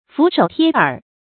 伏首貼耳 注音： ㄈㄨˊ ㄕㄡˇ ㄊㄧㄝ ㄦˇ 讀音讀法： 意思解釋： 畏縮恐懼的樣子。